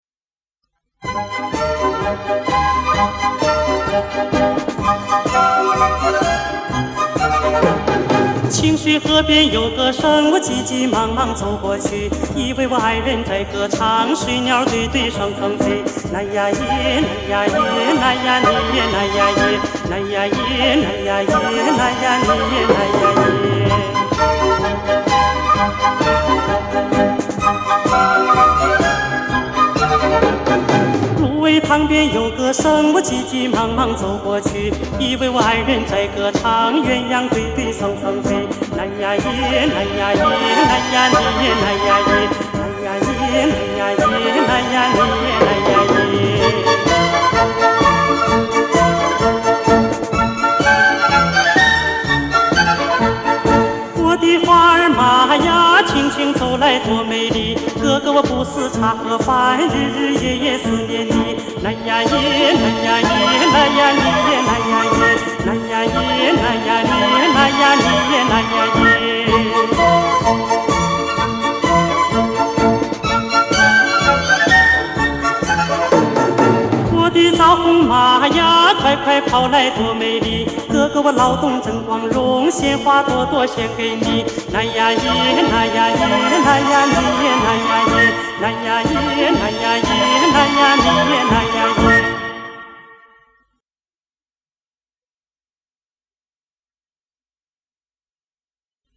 达斡尔族民歌
中国歌剧舞剧院小乐队伴奏